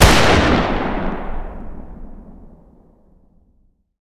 fire-dist-44mag-pistol-ext-05.ogg